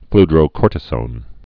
(fldrō-kôrtĭ-sōn, -zōn)